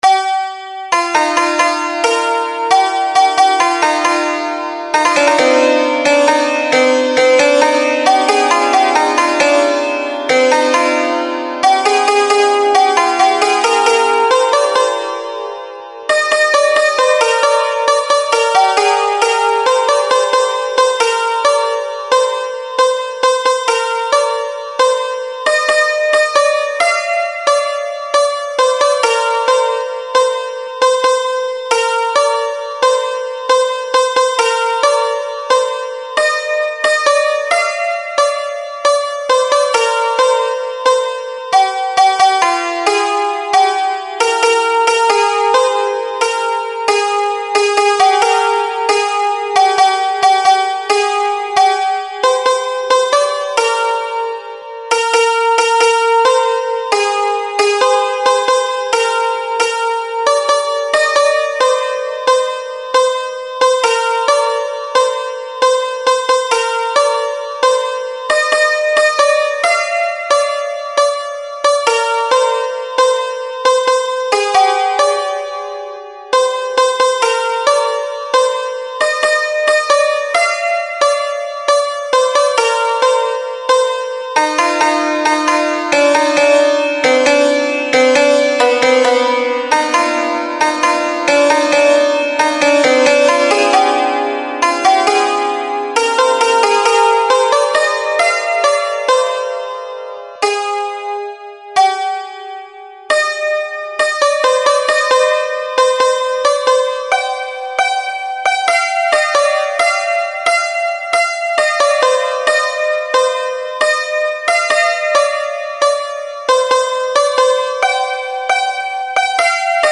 ساز: سنتور